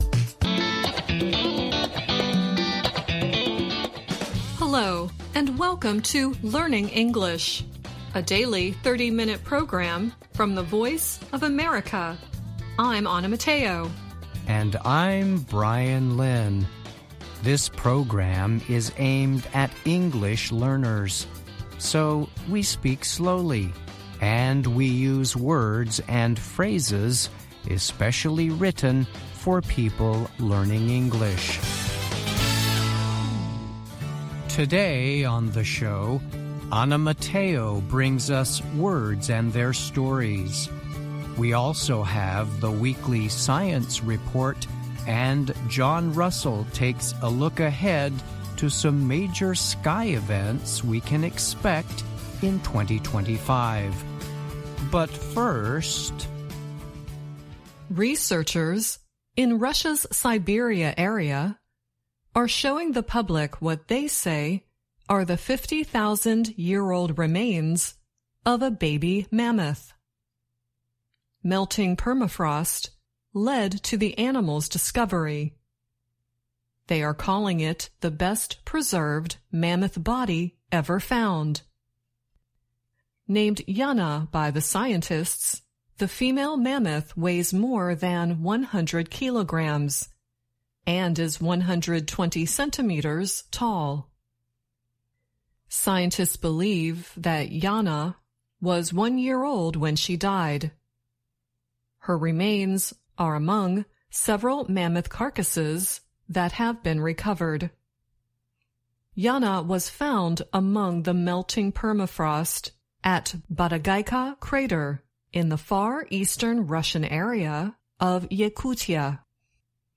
Learning English programs use a limited vocabulary and short sentences. They are read at a slower pace than VOA's other English broadcasts.